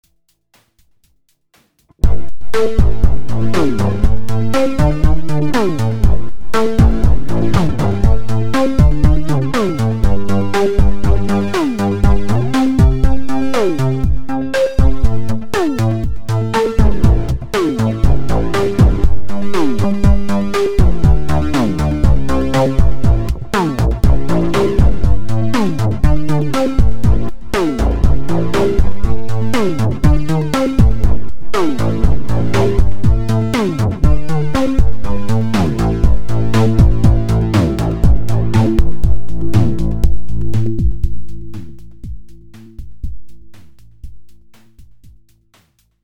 Far from perfect but it kind of works. No samples.
Guitar > AH L > Input A (kick*, bass, guitar)
AH R > Input B (noise for snare, hh, with lfo on Q :wink:)
*Exp lfo on Comb Filter’s pitch, filter envelope.